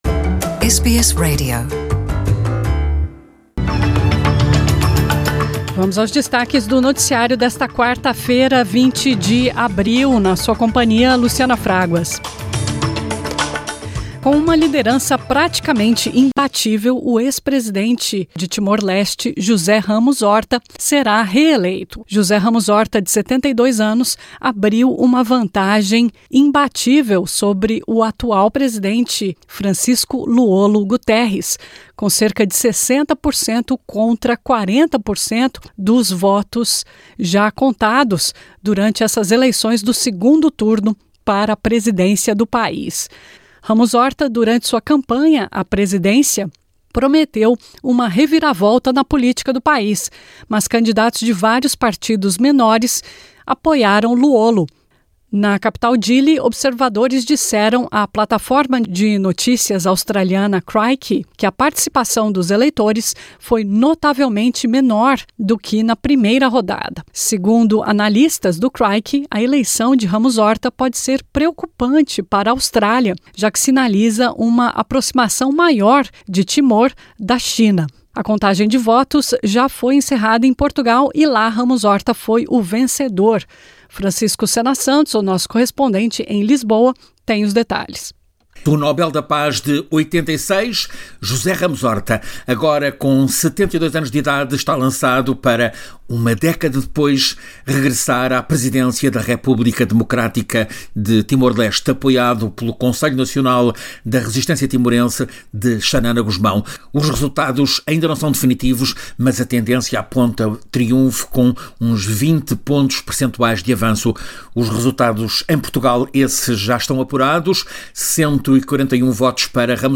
As notícias da Rádio SBS, da Austrália e do mundo, para esta quarta-feira.